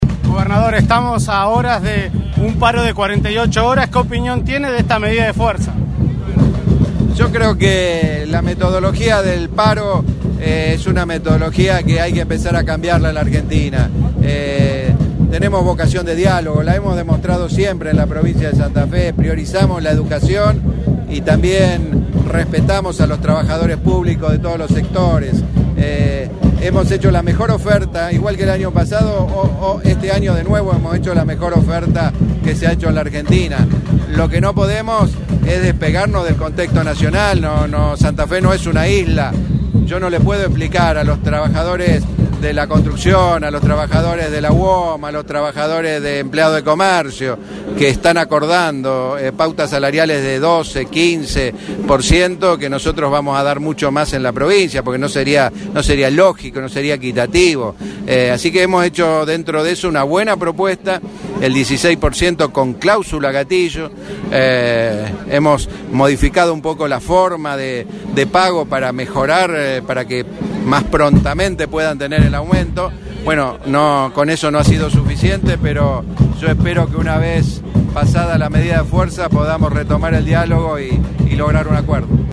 Miguel Lifschitz recorrió varias instituciones de la ciudad de San Justo en la tarde noche del lunes, en ronda de prensa se refirió al paro de 48 horas.
Miguel Lifschitz en diálogo con RADIO EME San Justo: